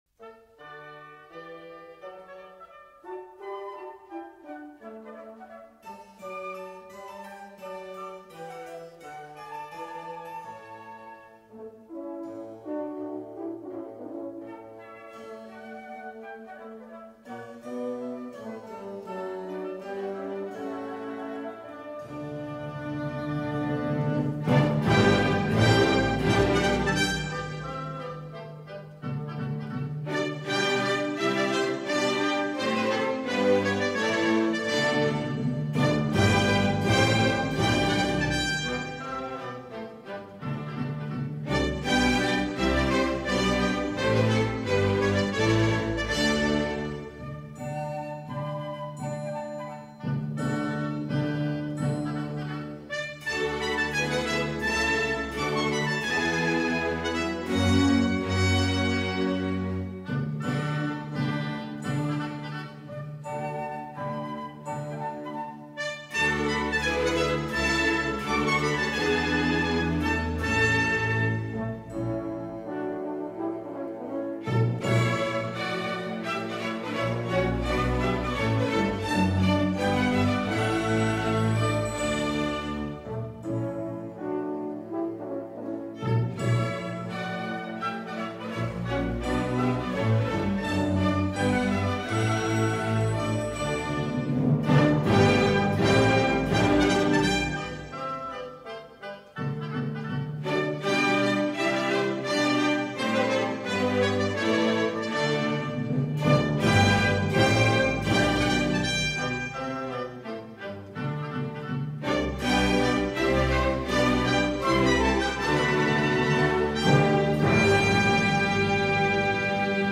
elaborada partitura barroca